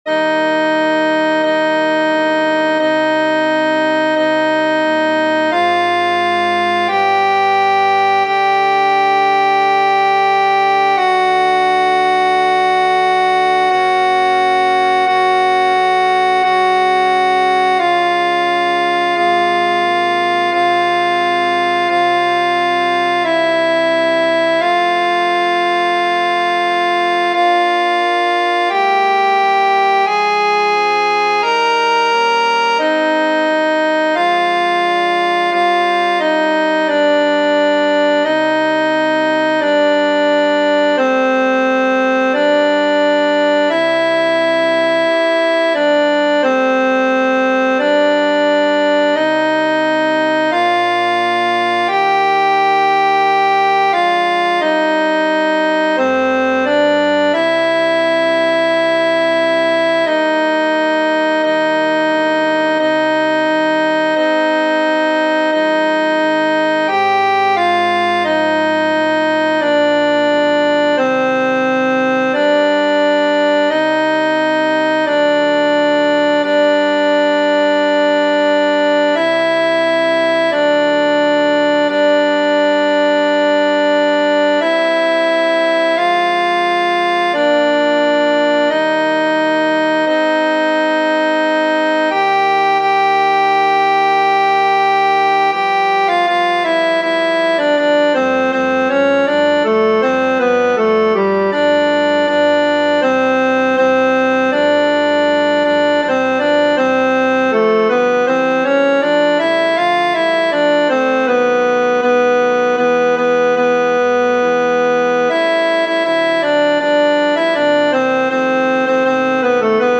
Pisen_ceska-T1.mp3